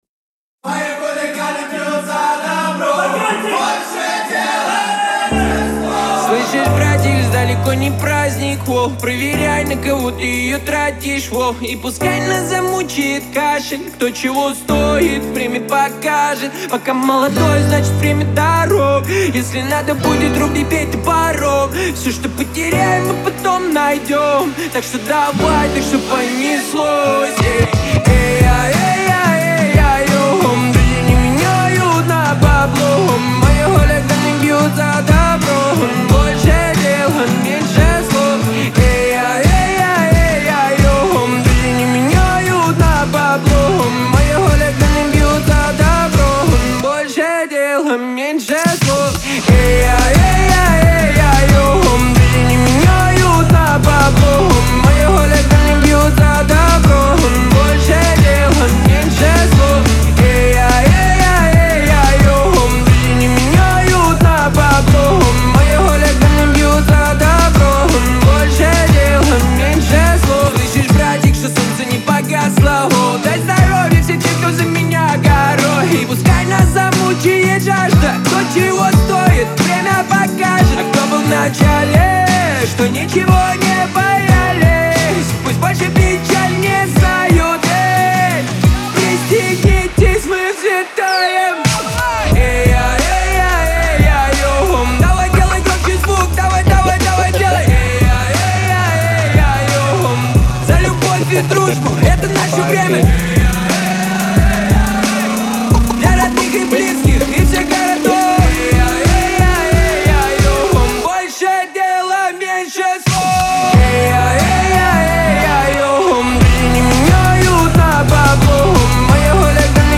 мелодичный вокал